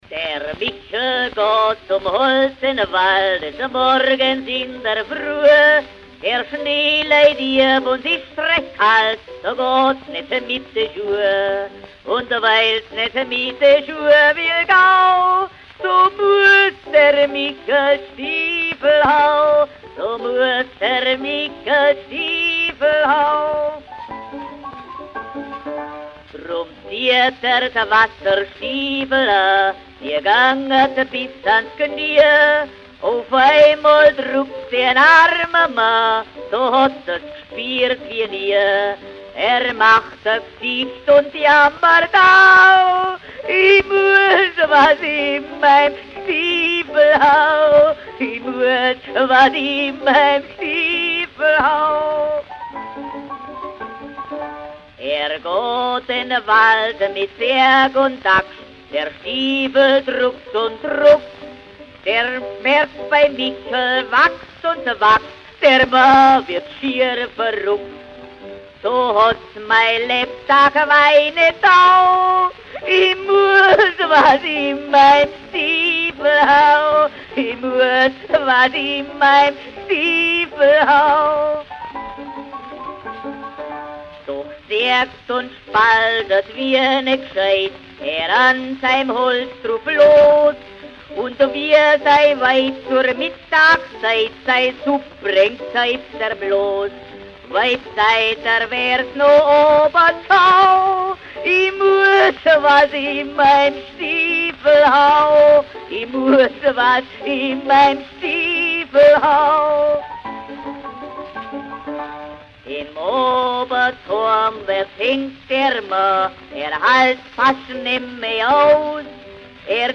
Schellackplattensammlung